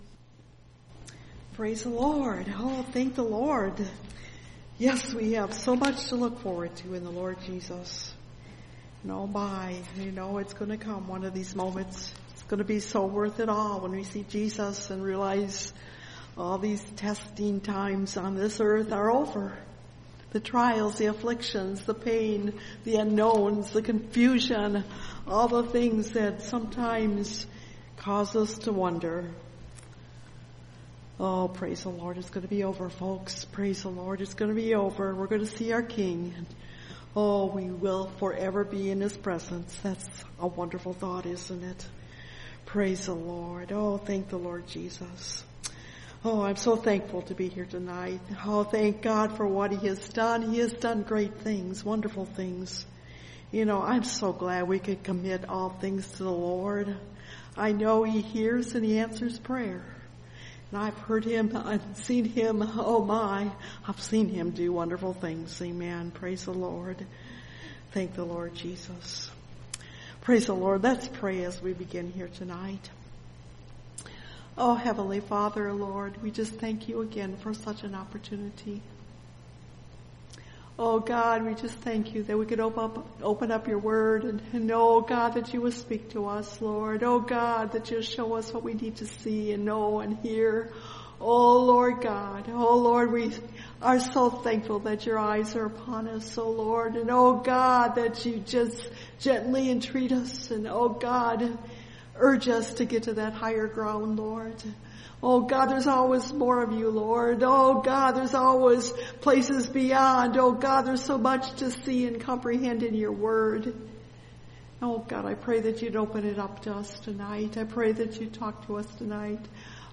When God Saw Their Works (Message Audio) – Last Trumpet Ministries – Truth Tabernacle – Sermon Library